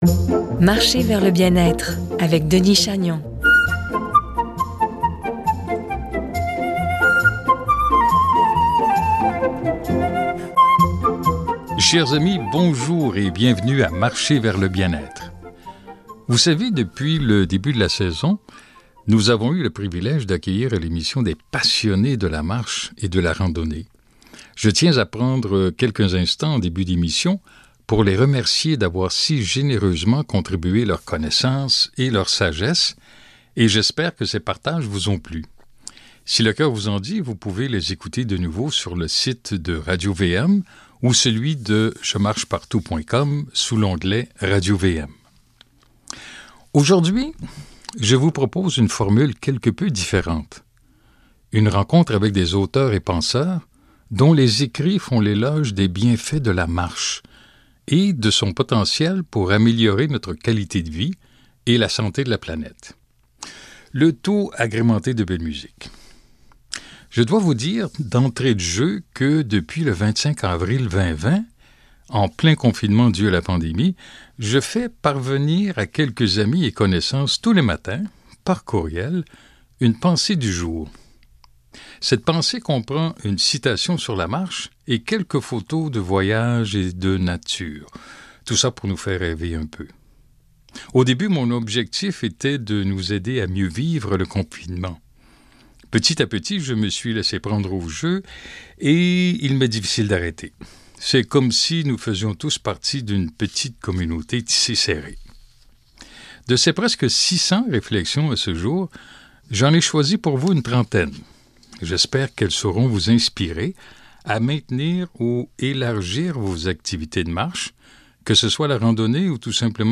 26 octobre 2021 — Des portraits d’auteurs et de penseurs dont les écrits font l’éloge des bienfaits de la marche, source d’inspiration pour améliorer notre qualité de vie et la santé de la planète, et pour nous faire sourire. Le tout agrémenté de belle musique…